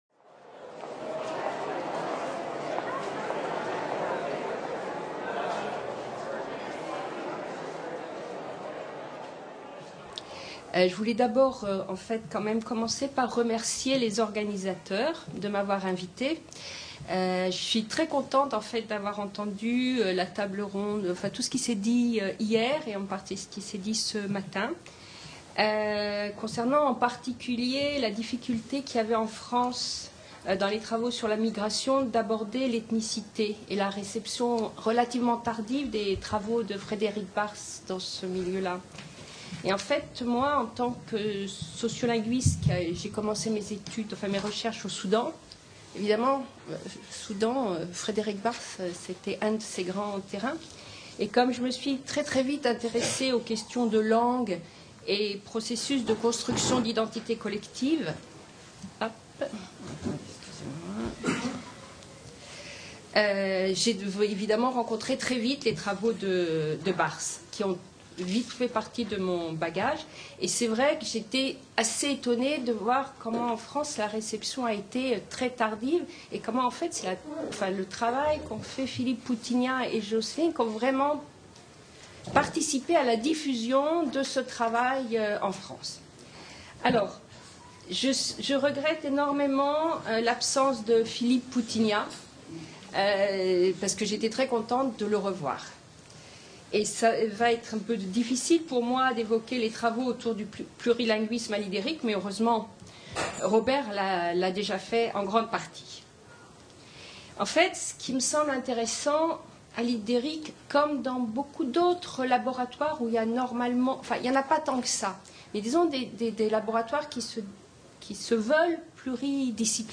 Intervention sur les travaux sur le plurilinguisme à l'Ideric